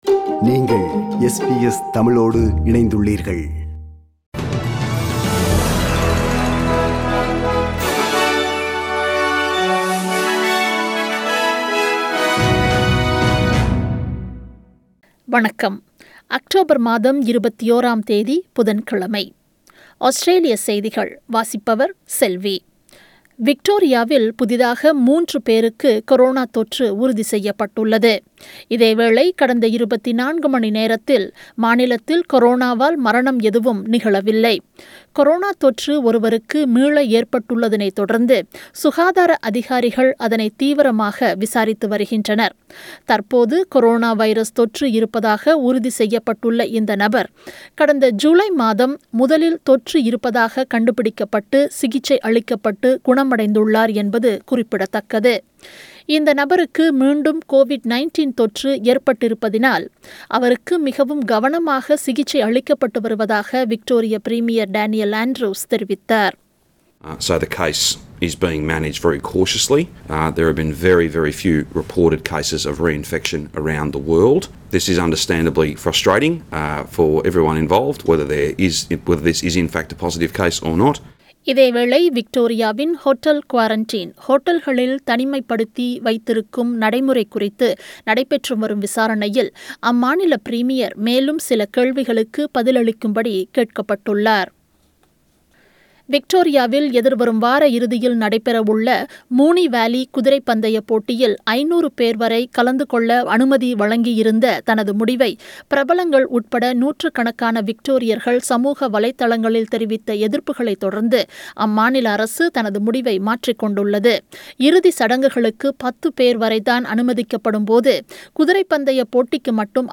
Australian news bulletin for Wednesday 21 October 2020.